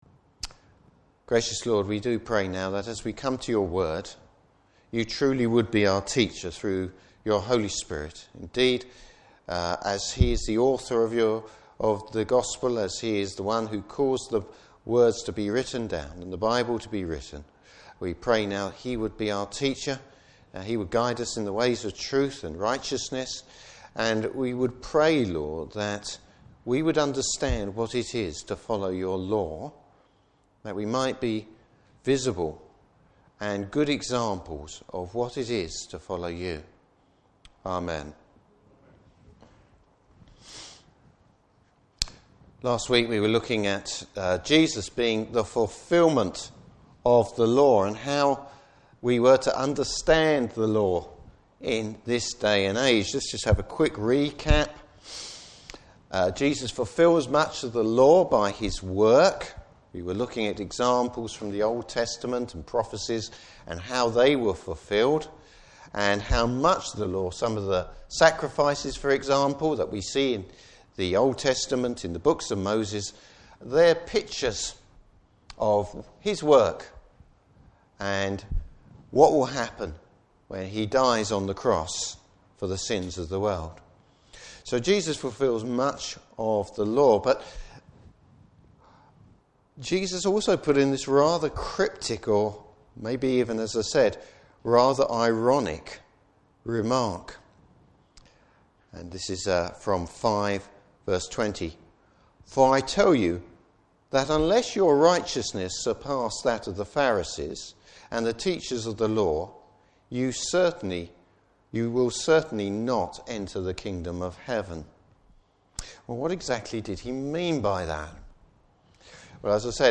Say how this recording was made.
Service Type: Morning Service Bible Text: Matthew 5:21-30.